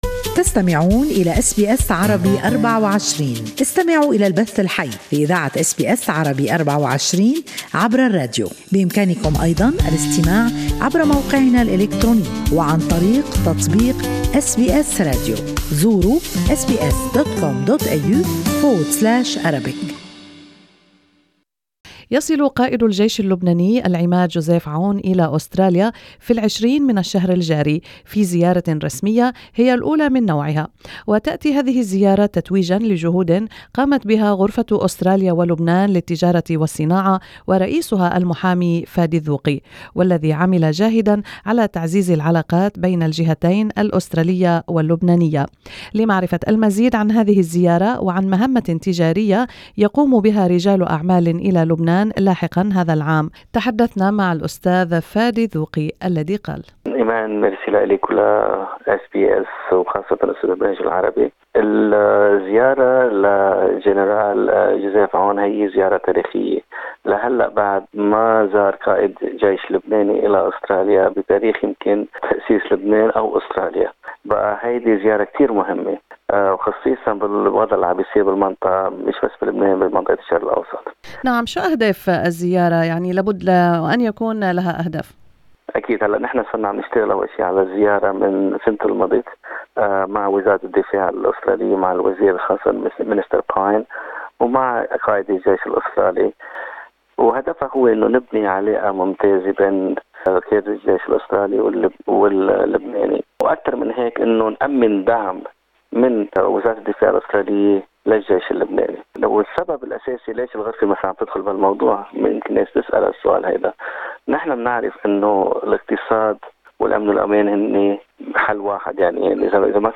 This interview in Arabic sheds light on this visit and on a trade mission to Lebanon that the ALCCI is preparing for and which will take place in August.